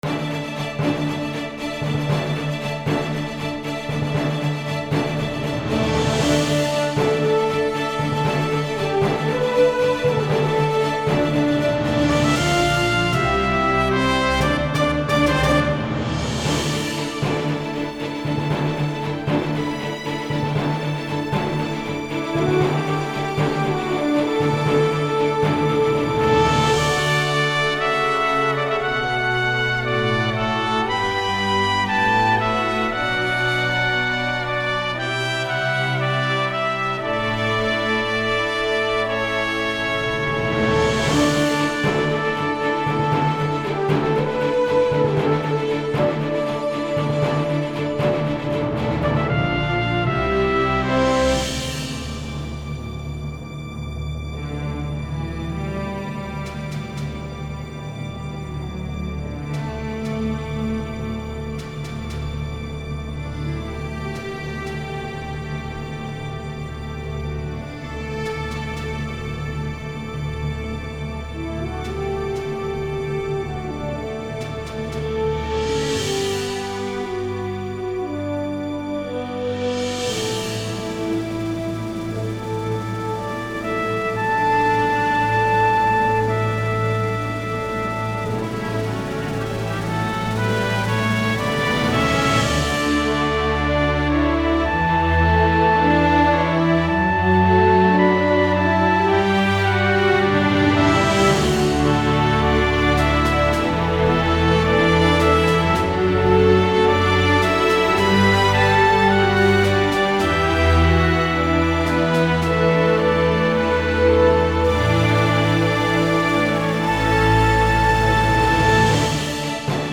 orchestral
bright
heroic